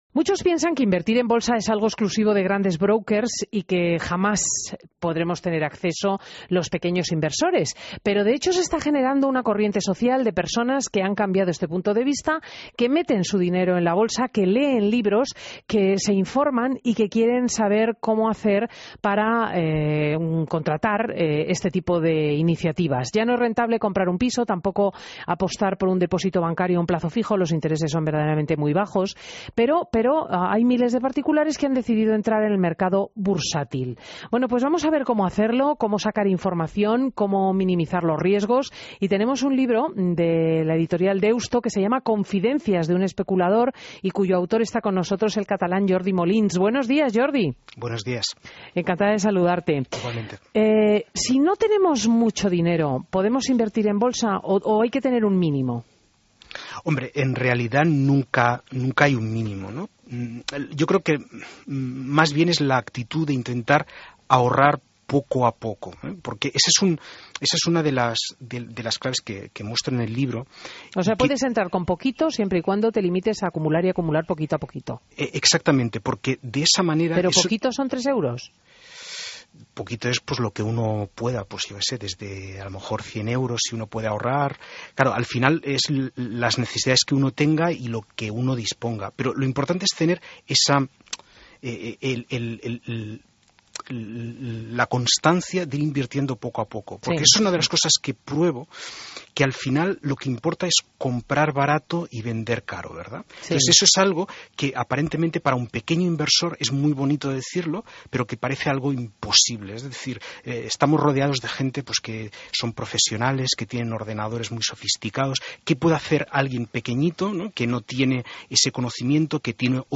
Entrevistas en Fin de Semana